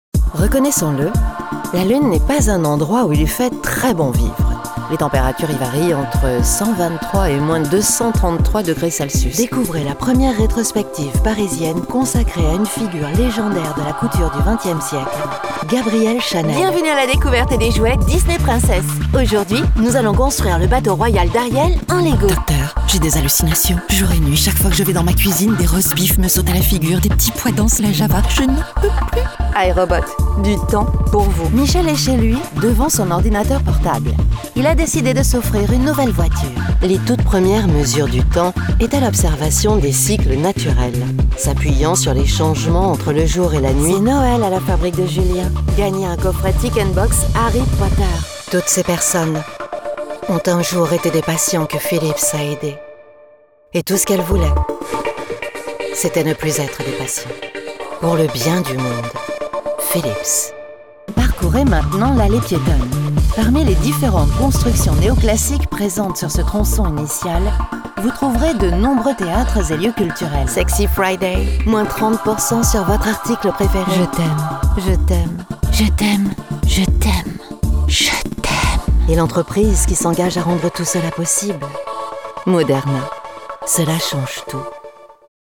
Female
Approachable, Confident, Corporate, Friendly, Reassuring, Versatile, Warm
Commercial-Digital-Philips Healthcare.mp3
Microphone: Neumann TLM 103
Audio equipment: RME Fireface UC, separate Soundproof whisper room